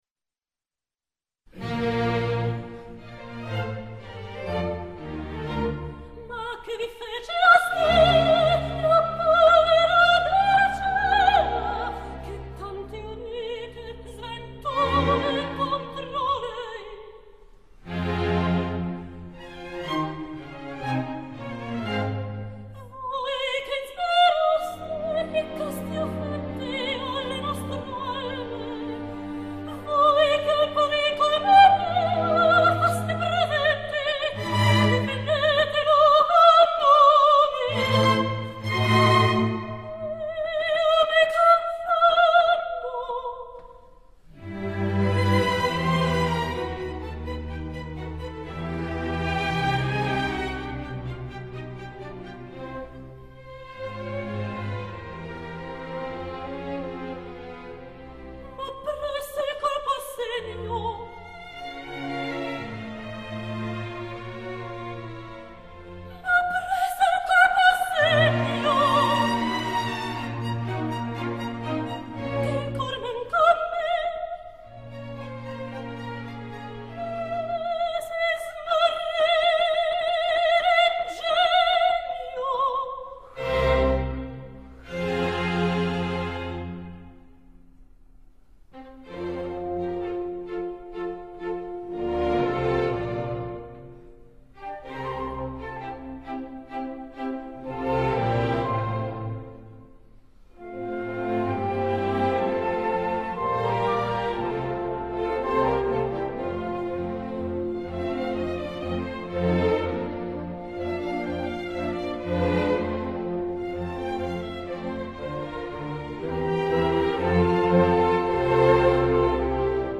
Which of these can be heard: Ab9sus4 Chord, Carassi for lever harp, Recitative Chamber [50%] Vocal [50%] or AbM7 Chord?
Recitative Chamber [50%] Vocal [50%]